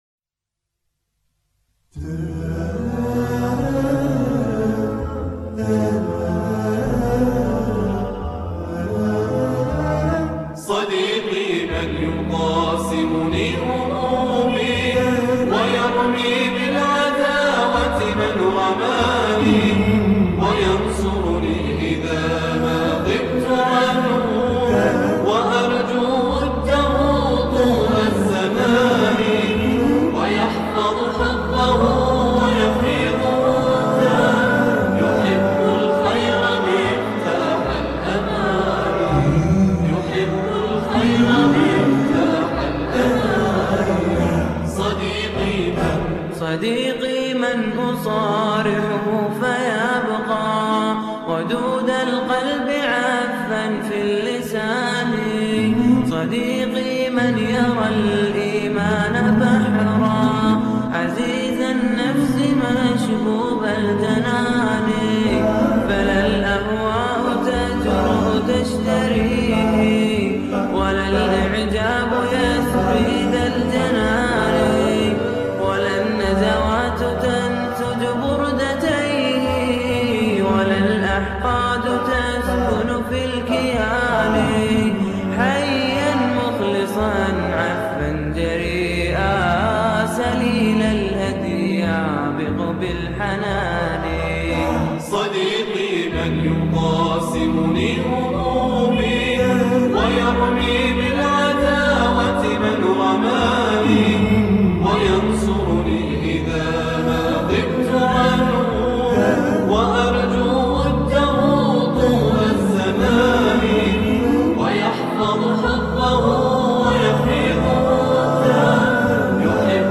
Description: - Tags: Ù†Ø´ÙŠØ¯ ( 0 ) ( 0 Votes ) 1 2 3 4 5 Bookmark Blink Digg Furl Deli Google Report Abuse Add Favorites Download audio